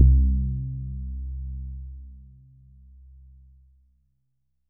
SPOOKY C1.wav